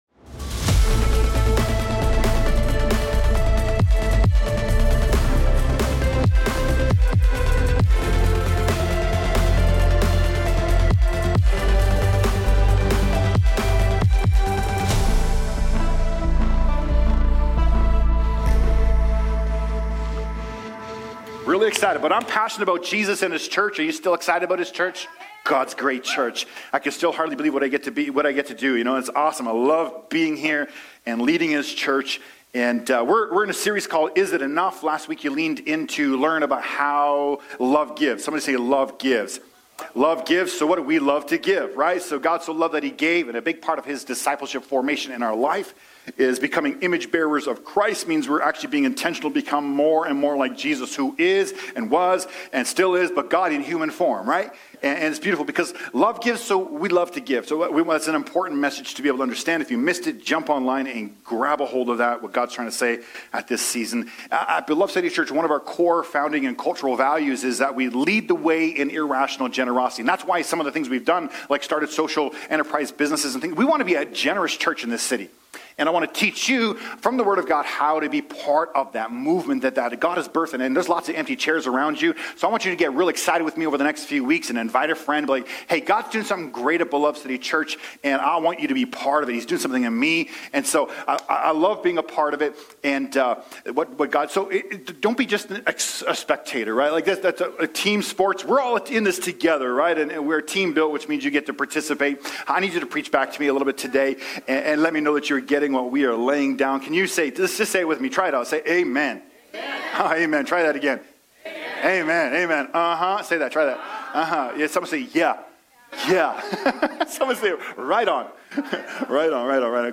2025 Current Sermon Are You Generous?